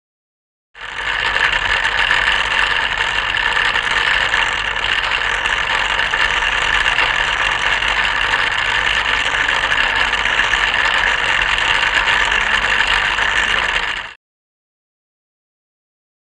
Loom; Hand Looms Running.